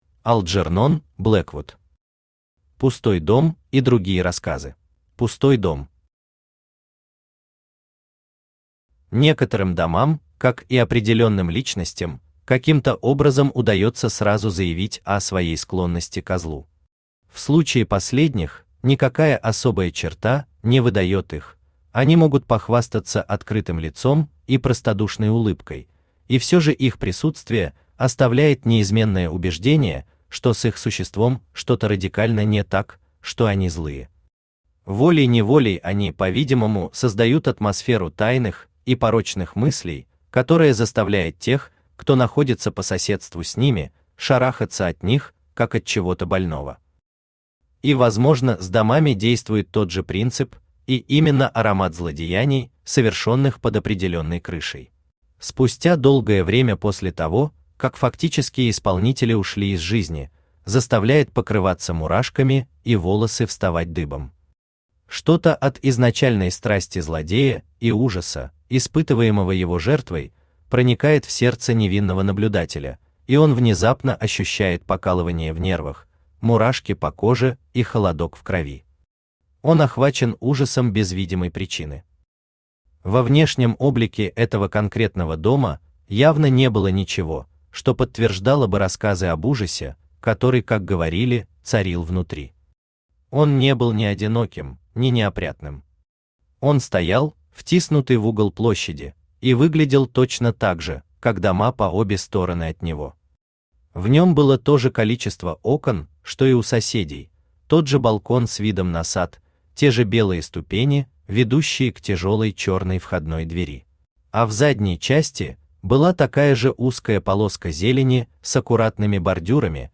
Аудиокнига Пустой дом и другие рассказы | Библиотека аудиокниг
Aудиокнига Пустой дом и другие рассказы Автор Алджернон Блэквуд Читает аудиокнигу Авточтец ЛитРес.